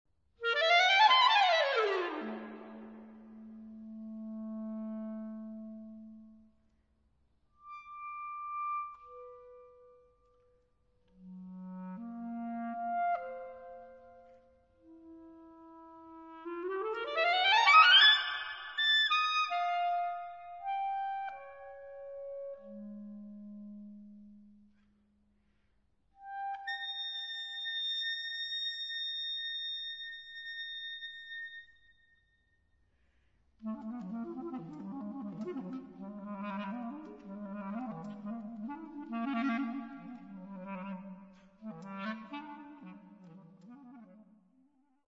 Clarinet
Hospital of St Cross, Winchester